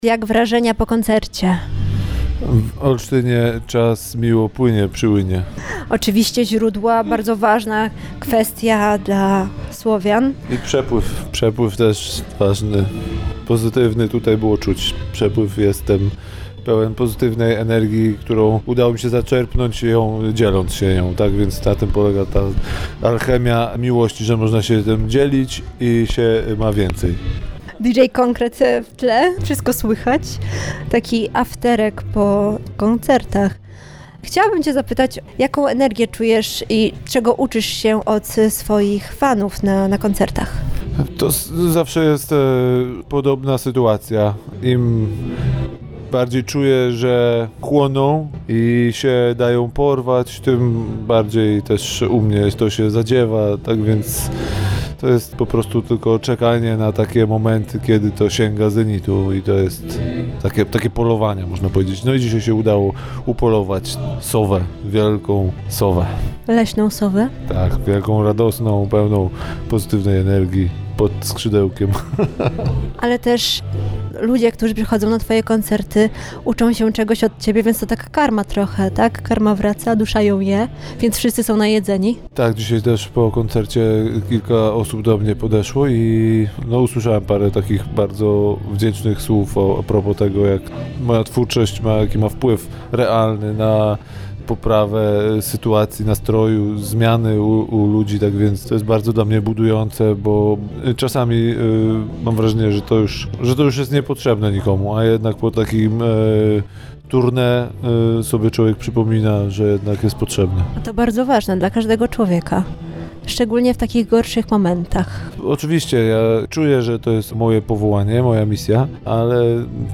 Podzielił się z nią swoimi przemyśleniami na temat lasów, kultury słowiańskiej, a także opowiedział o swoich planach i ostatnich życiowych doświadczeniach. Zapraszamy do wysłuchania tej inspirującej rozmowy!